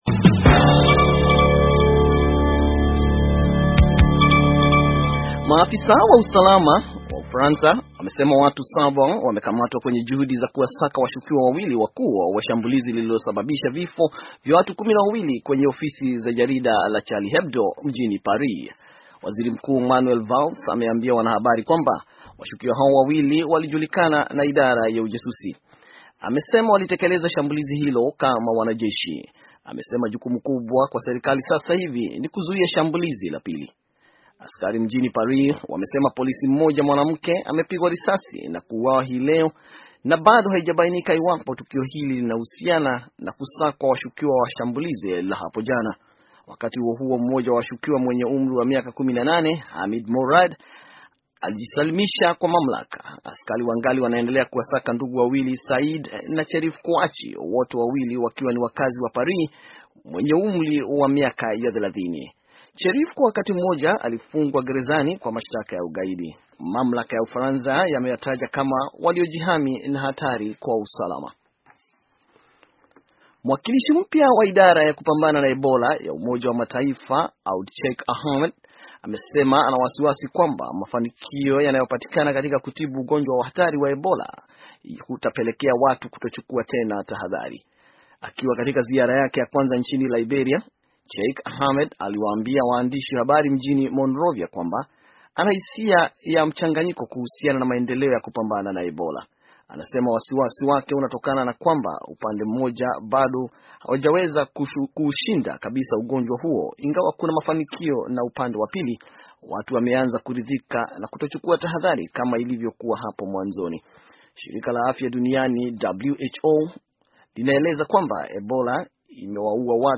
Taarifa ya habari - 5:17